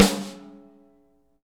Index of /90_sSampleCDs/Best Service - Real Mega Drums VOL-1/Partition D/AMB KIT 02EC